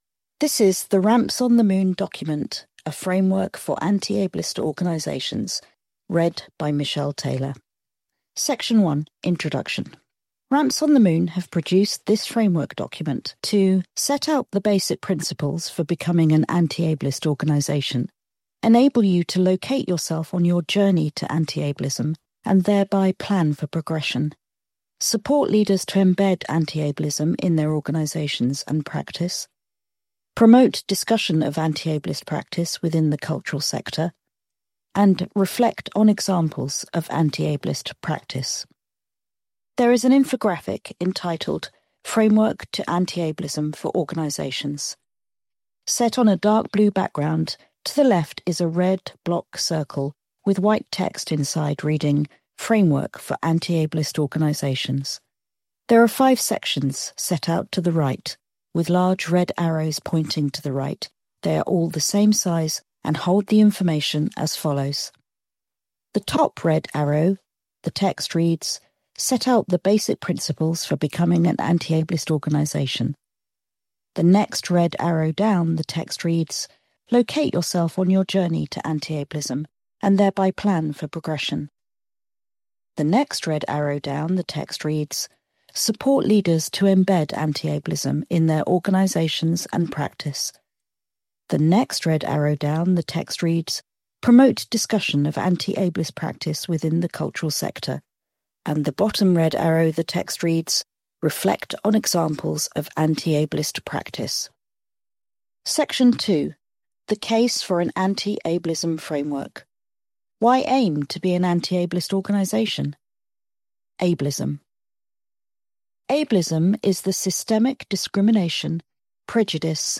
Audio Description please listen here:
Audio-Description-Framework-for-Anti-Ableist-Organisations.mp3